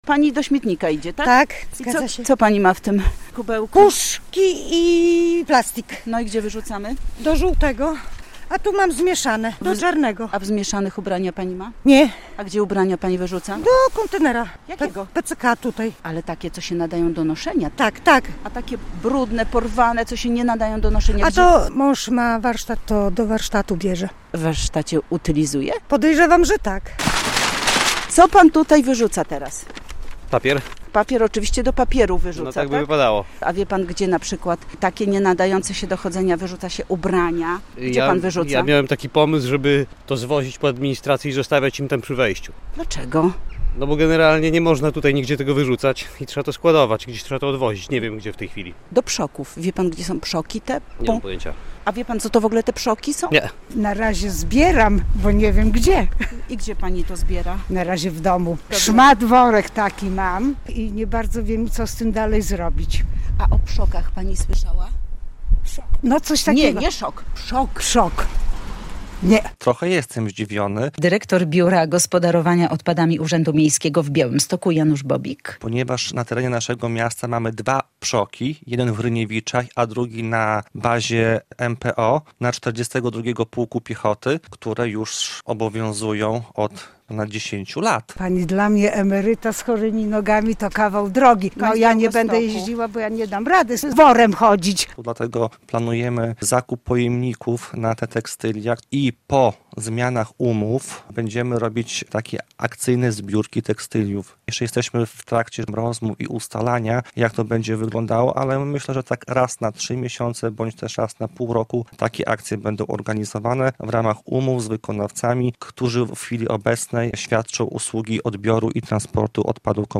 Pytaliśmy o to na jednym z białostockich osiedli.